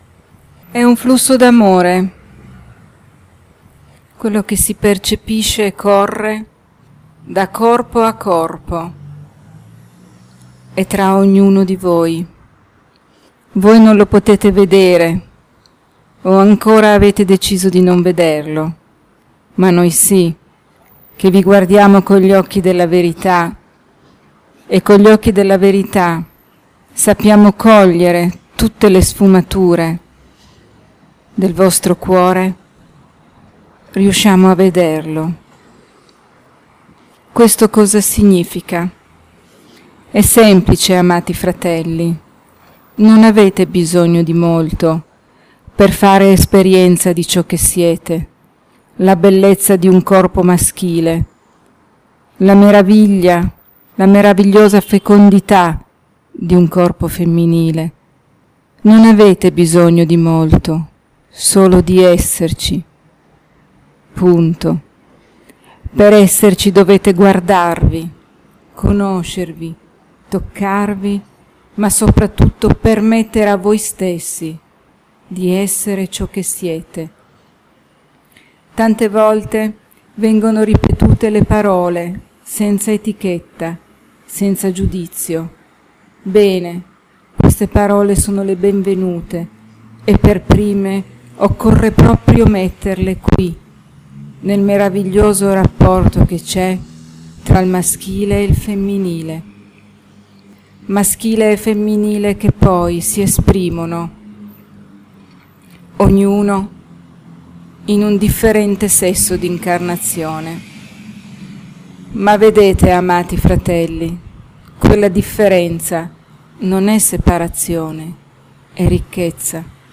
Canalizzazione effettuata al centro Opale di Gallarate durante la presentazione de Il Libro della Forza e del Nutrimento � un flusso d�amore quello che si percepisce e corre da corpo a corpo e tra ognuno di voi.